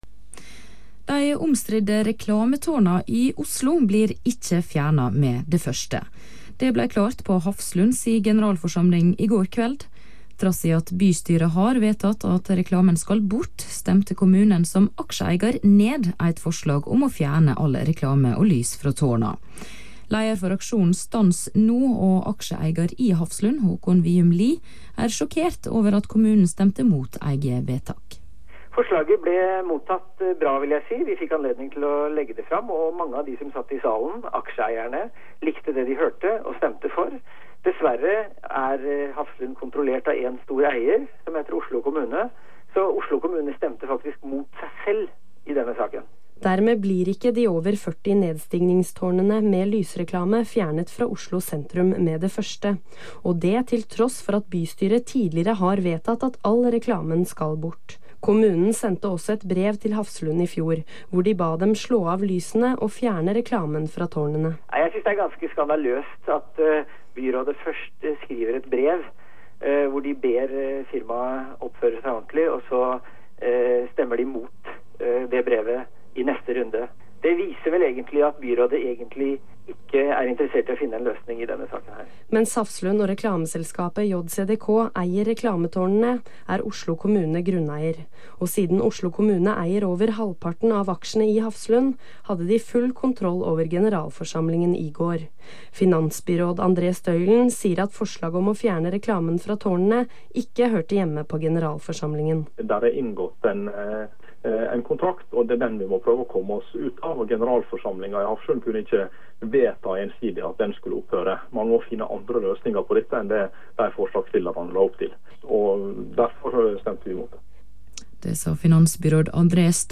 2007-05-03: Stans!no på Hafslunds generalforsamling: Hafslund må oppgi okkuperte områder på byens fortau!